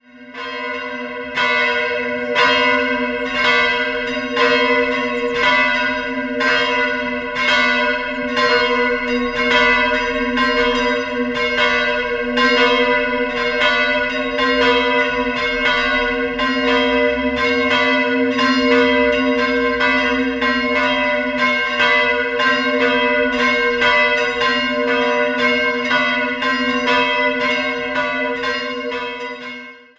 Die kleine Glocke wurde in der ersten Hälfte des 14. Jahrhunderts, evtl. in Nürnberg, gegossen.
Um das Jahr 1500 entstand die große Glocke.